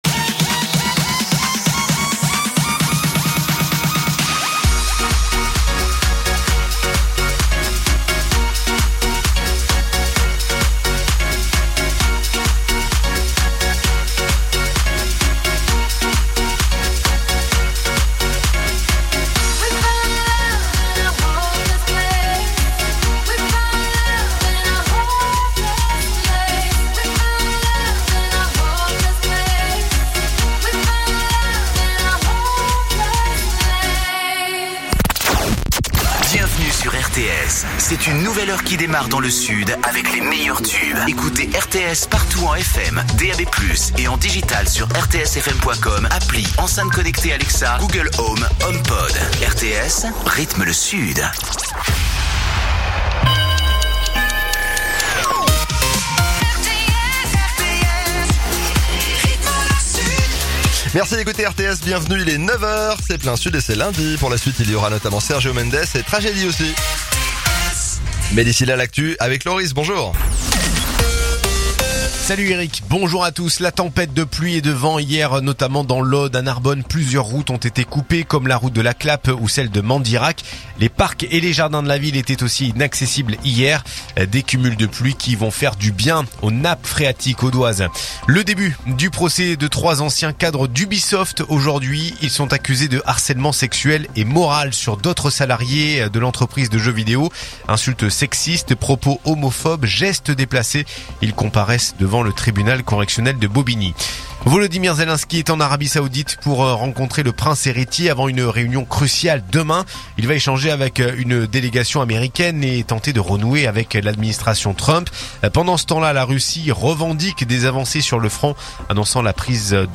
info_narbonne_toulouse_318.mp3